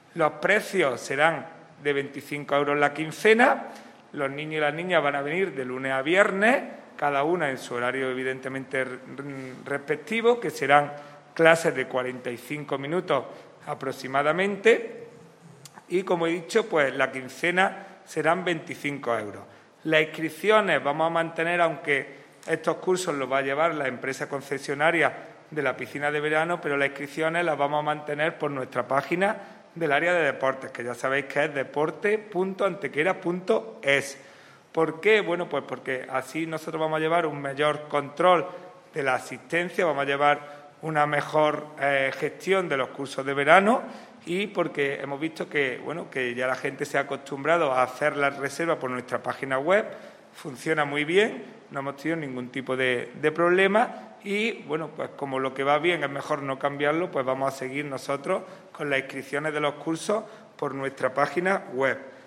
El teniente de alcalde delegado de Deportes, Juan Rosas, ha presentado esta mañana en rueda de prensa otra nueva iniciativa que vuelve a retomarse con la llegada del verano y que sigue con la pretensión de tratar de impulsar a que la población, sea de la edad que sea, haga deporte como hábito saludable.
Cortes de voz